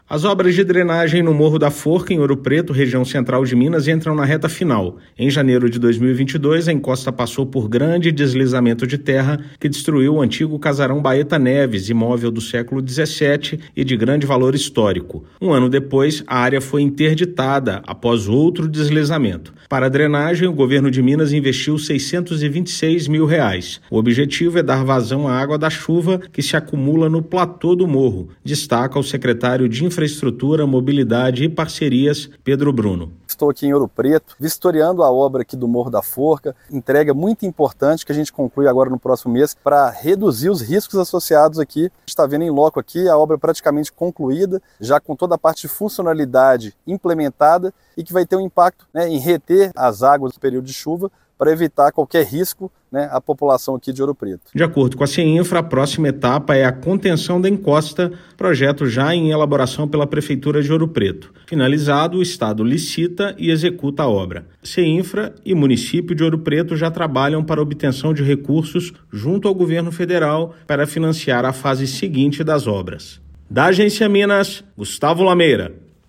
Foram investidos R$ 626 mil de recursos do Governo de Minas para escoar água pluvial na parte de cima do morro. Ouça matéria de rádio.